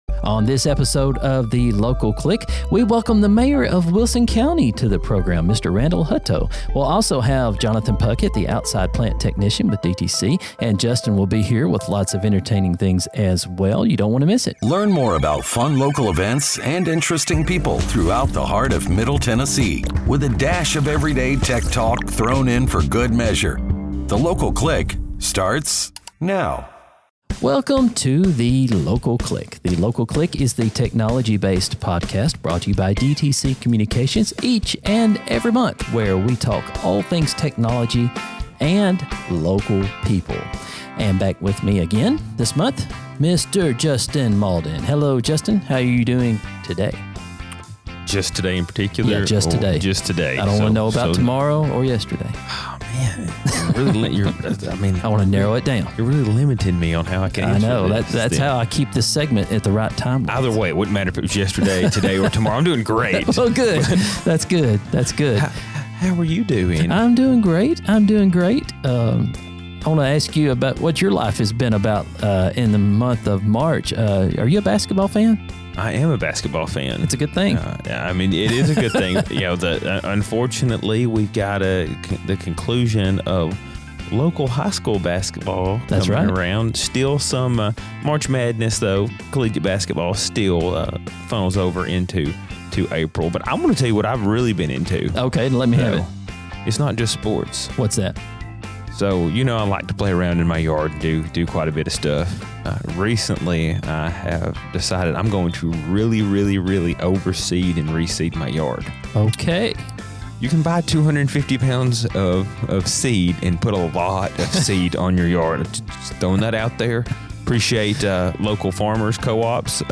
Wilson County Mayor Randall Hutto joins us to share updates on current county happenings and upcoming celebrations.